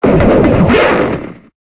Cri de Darumacho Mode Normal dans Pokémon Épée et Bouclier.